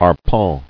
[ar·pent]